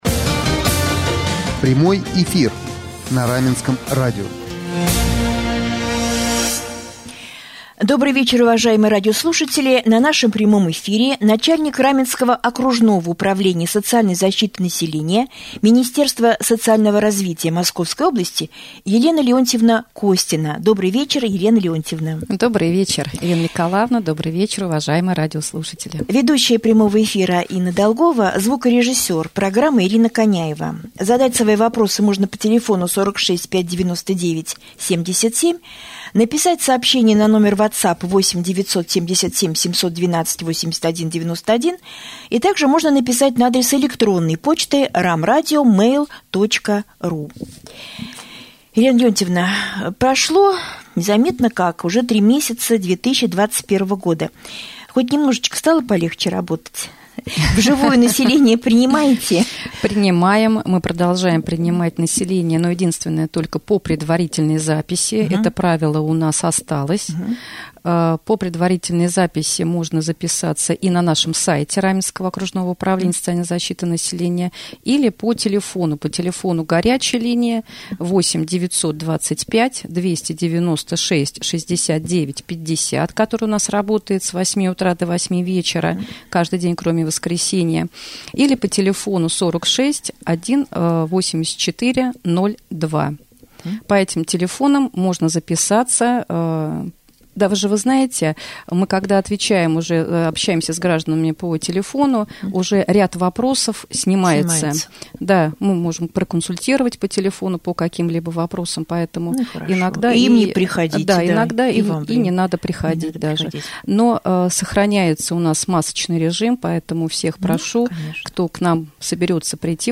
В прямом эфире на Раменском радио с Начальником Управления социальной защиты населения Еленой Костиной обсуждались вопросы:
2.prjamoj-jefir-soc.zashhita.mp3